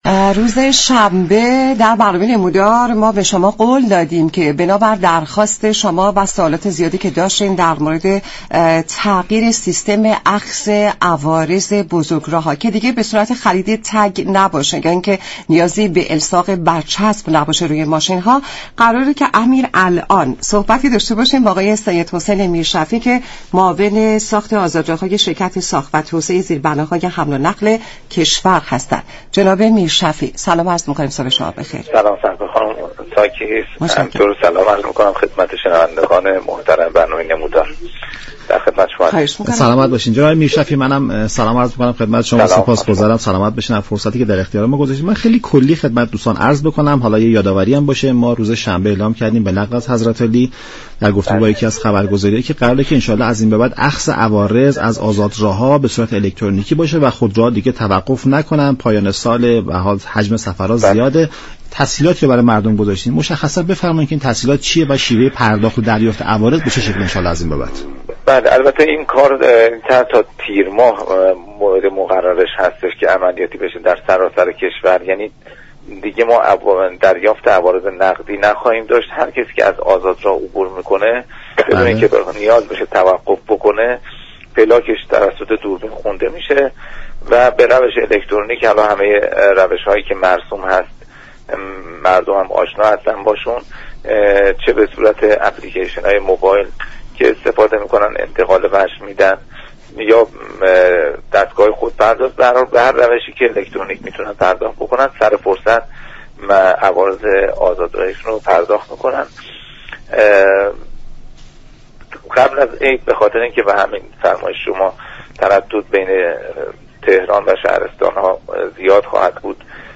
معاون ساخت و توسعه آزادراه‌ها در گفت و گو با برنامه نمودار گفت: طرح تغییر سیستم اخذ عوارض آزاد راه ها در طول ایام نوروز در مسیرهایی چون تهران- ساوه، كرج- قزوین، قزوین- زنجان، قزوین- رشت و تهران- قم به شكلی آزمایشی اجرا می شود.